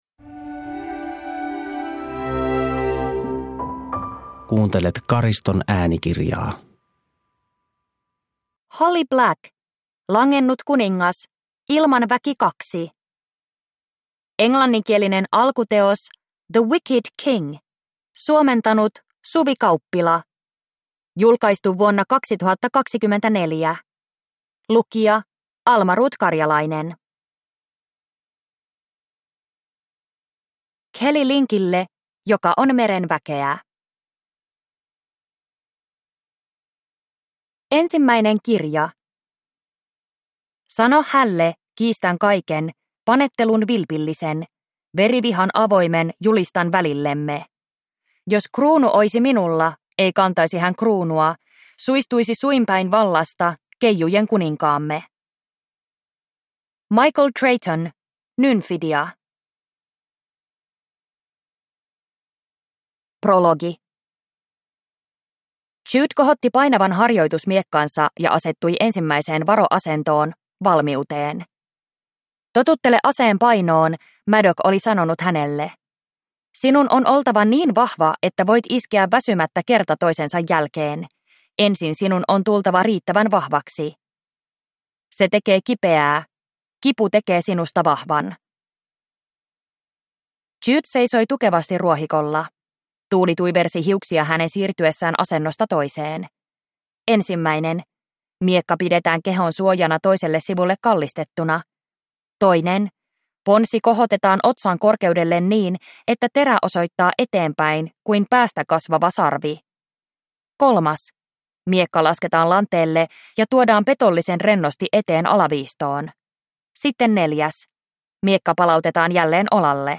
Langennut kuningas – Ljudbok